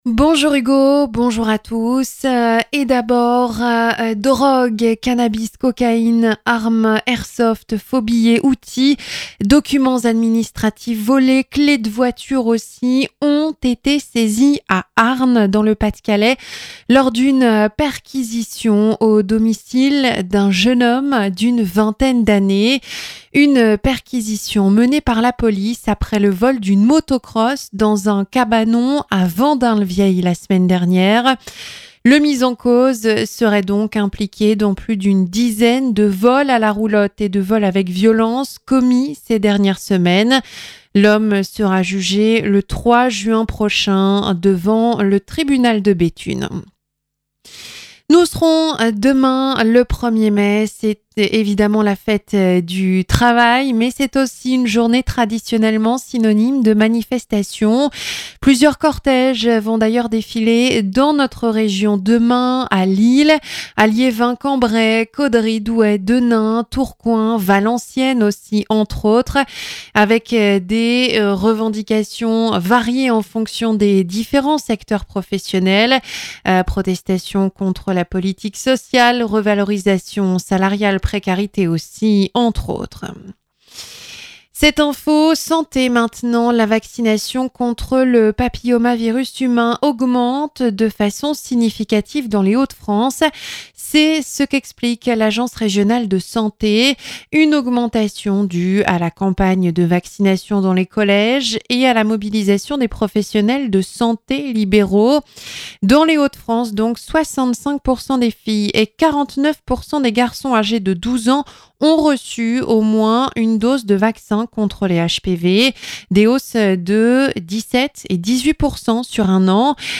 Journal 12h - De la drogue, une arme et des objets volés saisis à Harnes